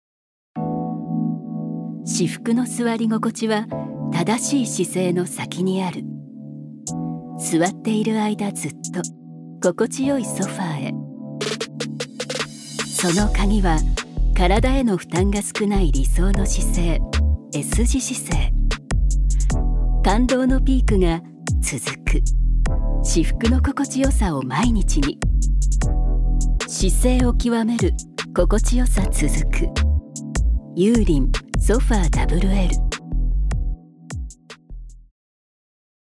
女性タレント
ナレーション２